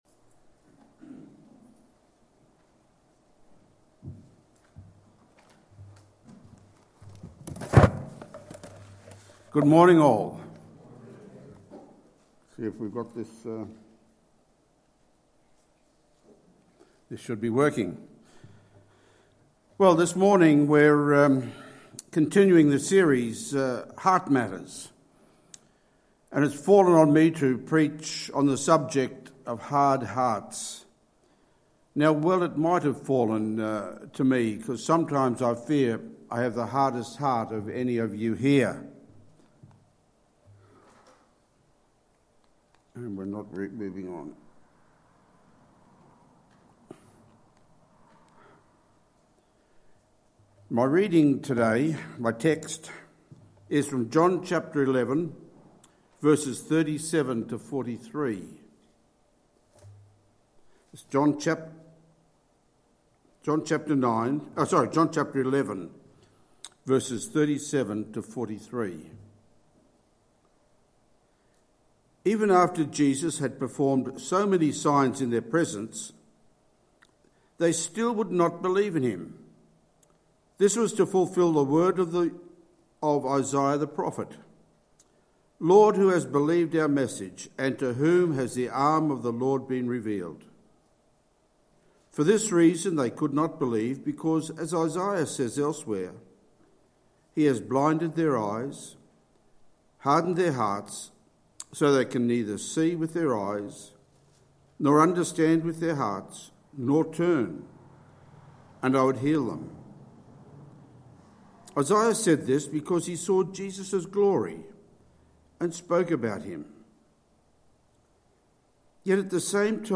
Sermons | Tenthill Baptist Church
20/11/2022 Sunday Service